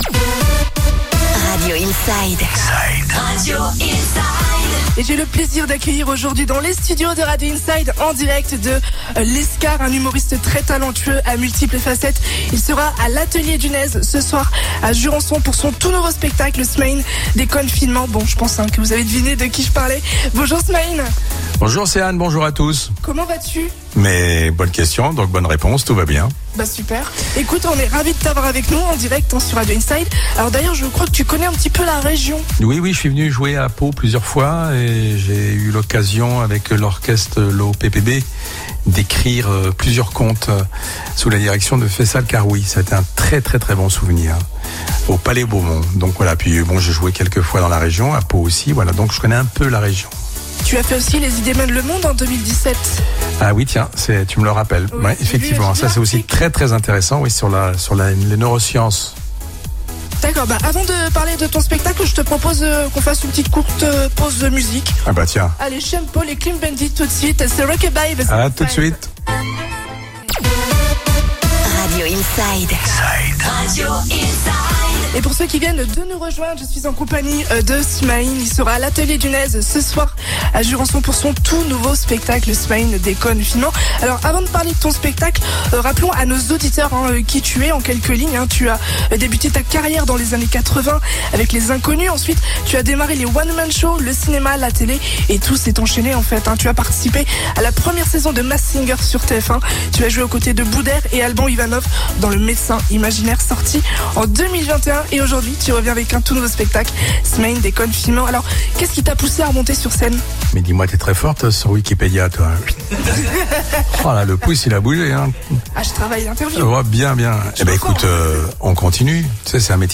Interview de Smaïn "Smaïn déconne finement" à Lescar, sur Radio Inside